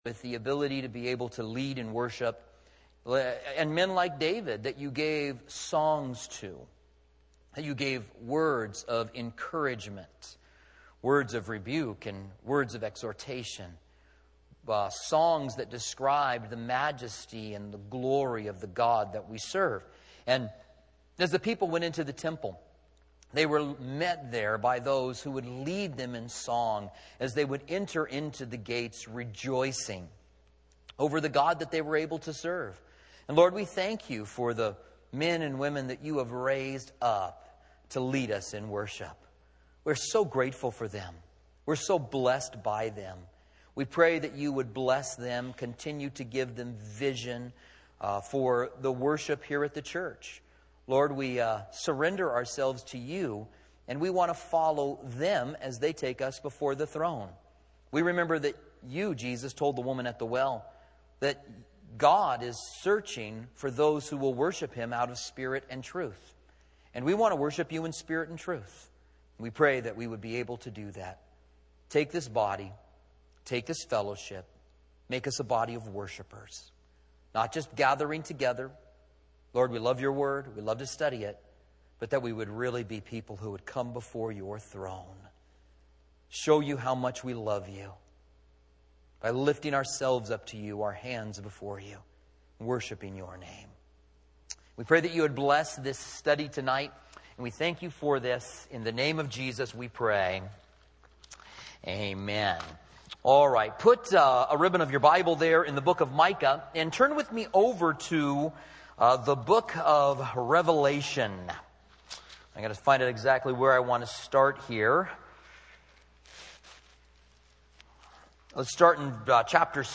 Commentary on Micah